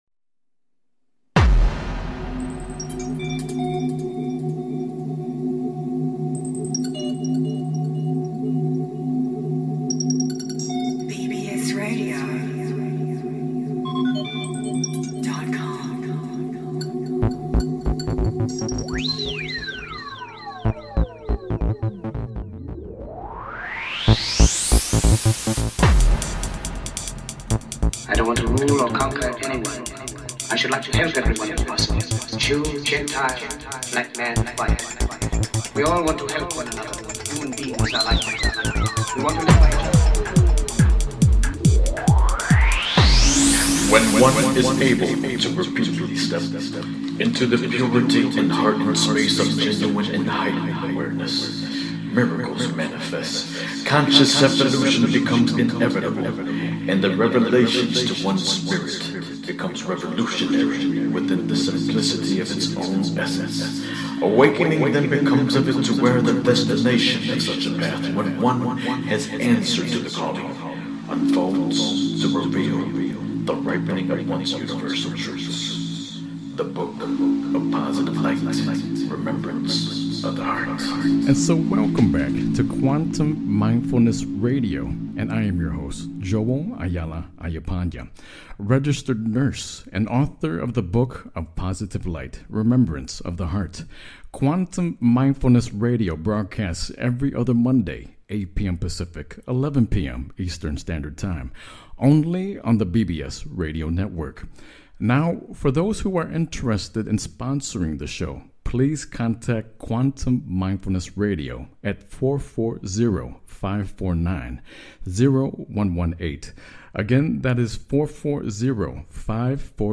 Headlined Show, Quantum Mindfulness Radio November 24, 2014